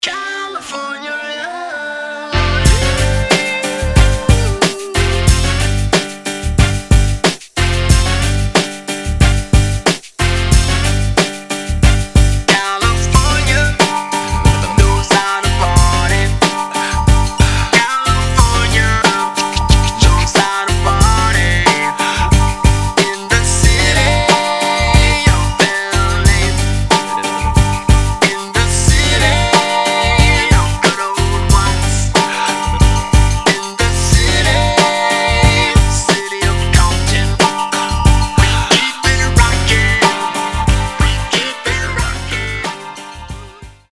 gangsta rap
хип-хоп